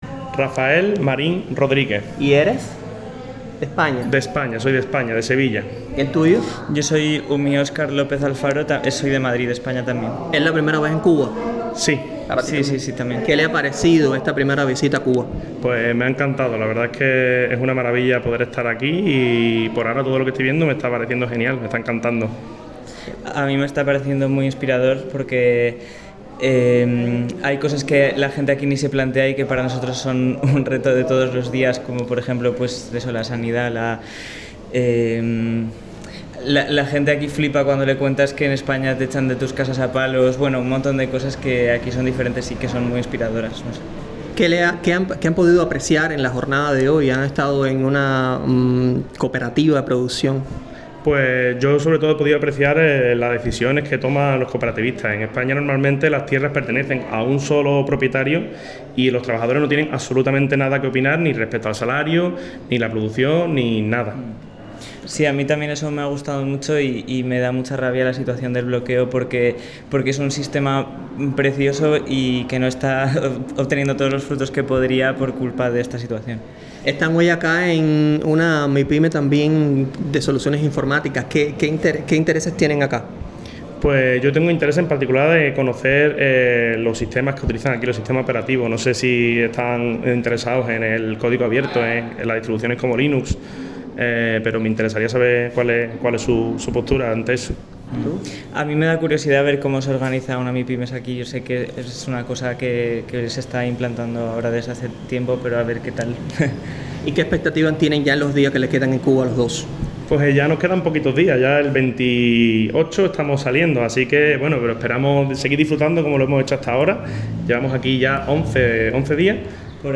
🎧 Opiniones de integrantes de la Brigada de Solidaridad José Martí
Entrevista-Brigadistas.mp3